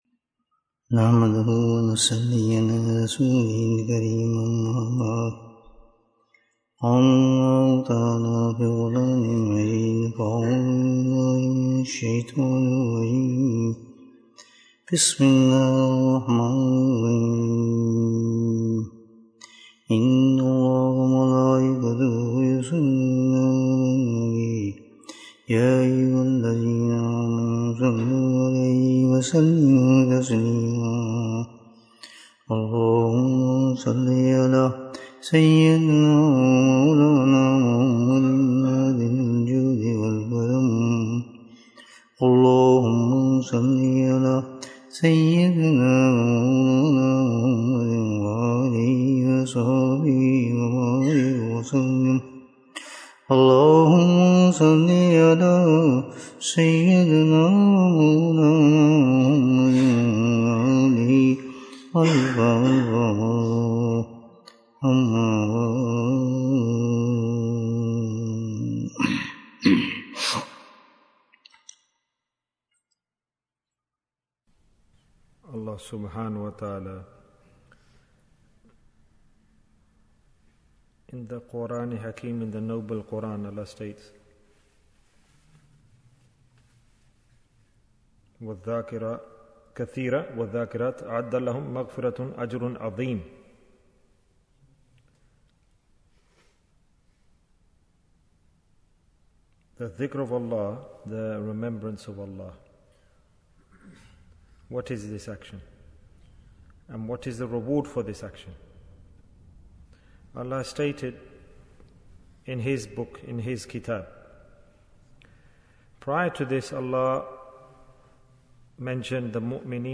Easy Deen Bayan, 31 minutes11th May, 2023